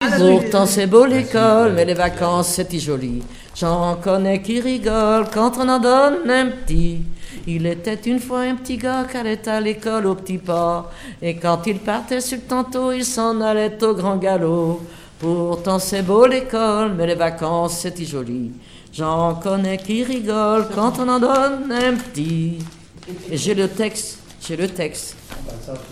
Enfantines diverses
répertoire de chansons traditionnelles
Pièce musicale inédite